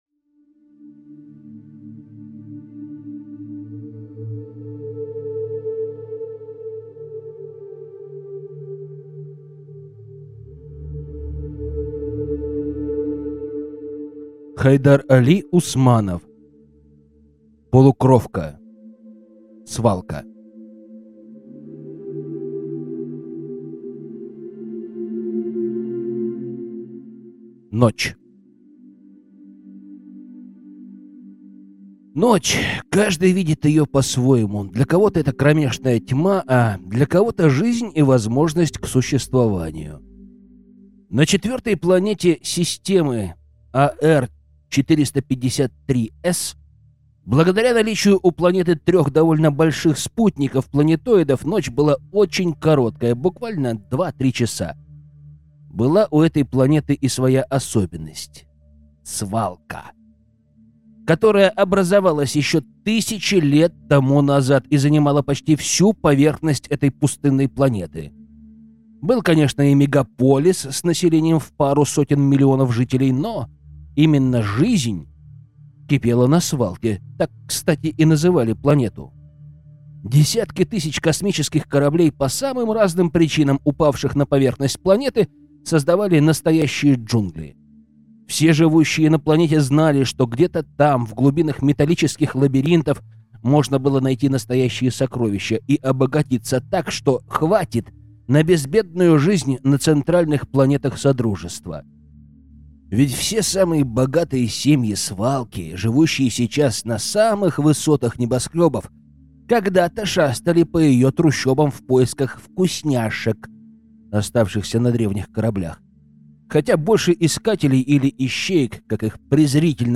Аудиокнига Полукровка. Свалка | Библиотека аудиокниг